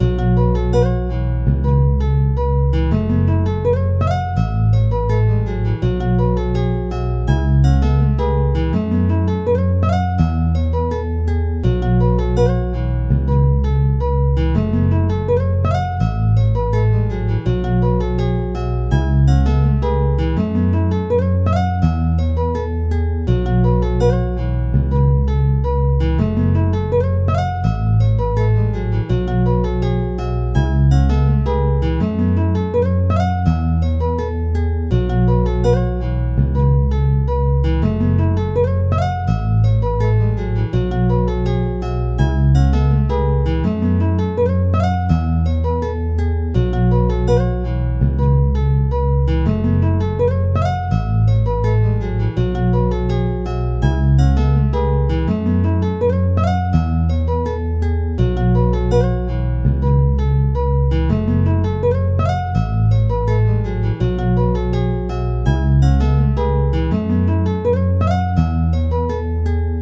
BGM
Speed 110%